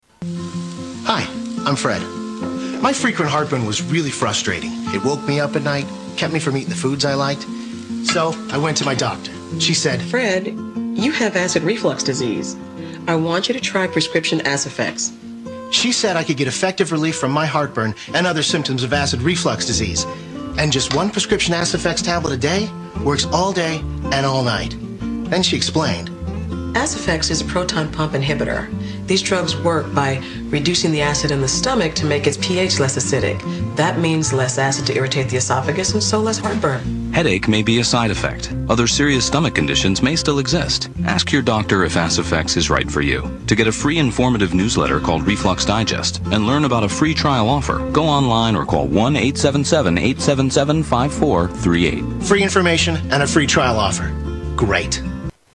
Aciphex TV ad